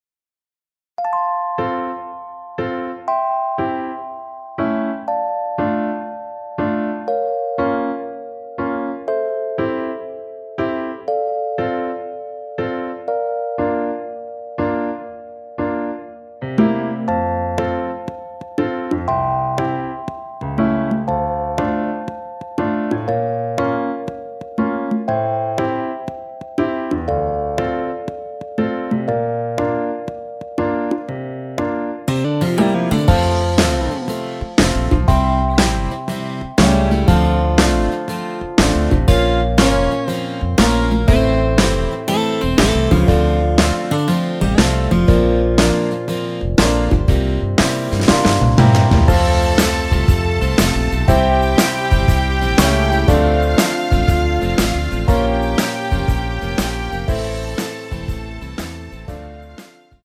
엔딩이 페이드 아웃이라 라이브 하시기 좋게 엔딩을 만들어 놓았습니다.
원키에서(-1)내린 MR입니다.
앞부분30초, 뒷부분30초씩 편집해서 올려 드리고 있습니다.
중간에 음이 끈어지고 다시 나오는 이유는